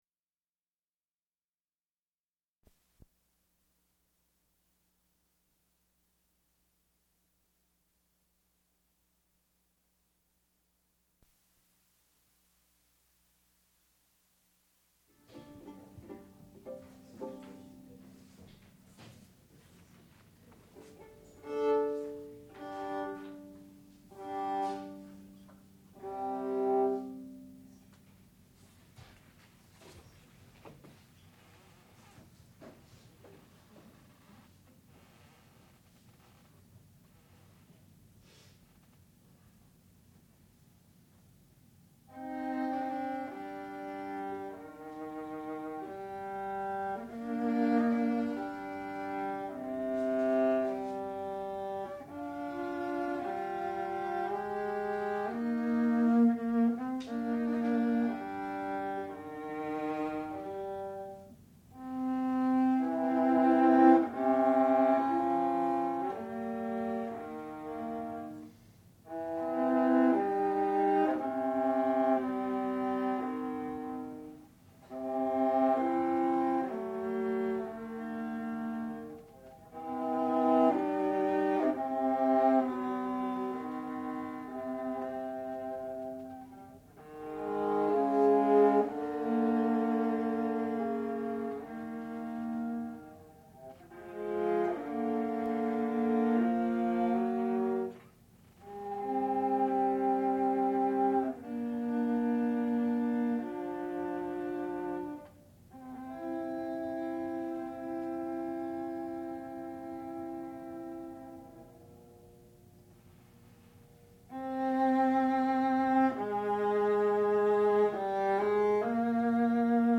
sound recording-musical
classical music
Advanced Recital
viola